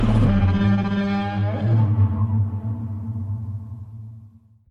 Godzilla Bellow Sound Buttons
Godzilla Bellow
godzilla_roar.mp3